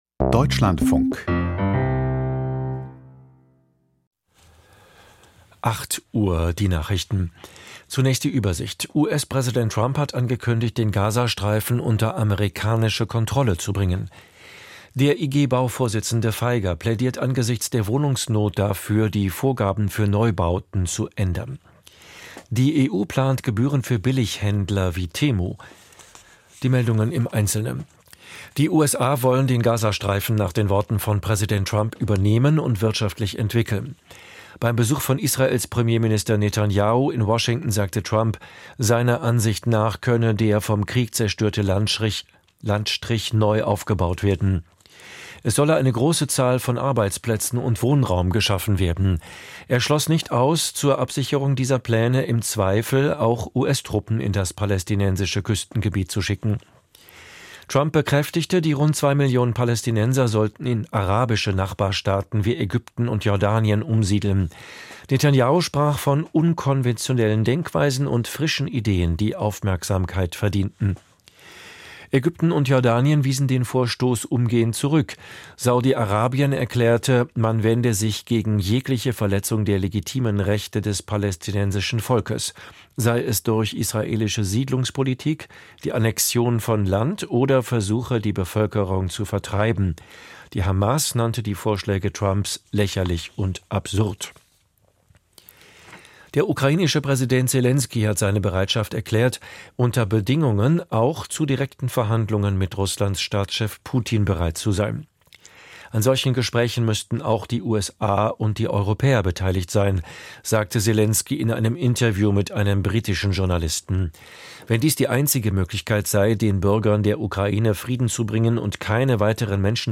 Die Deutschlandfunk-Nachrichten vom 05.02.2025, 07:59 Uhr